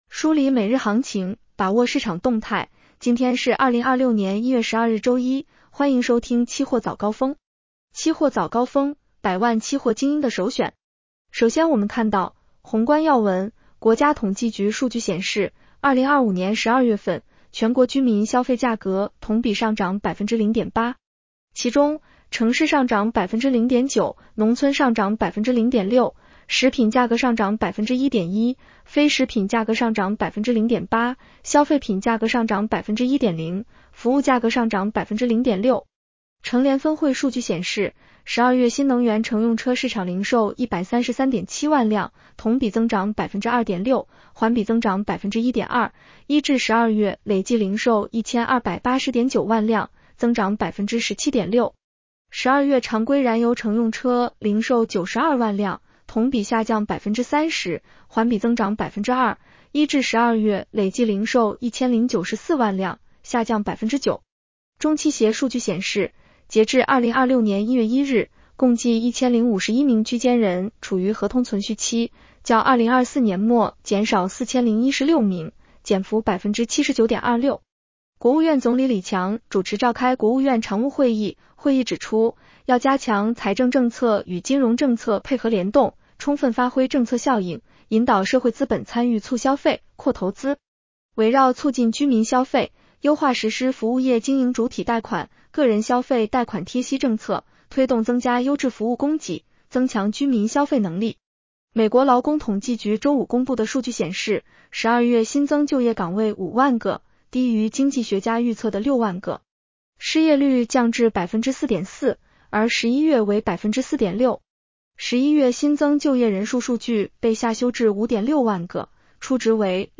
期货早高峰-音频版 女声普通话版 下载mp3 热点导读 1.广期所调整铂、钯期货合约涨跌停板幅度和交易保证金标准。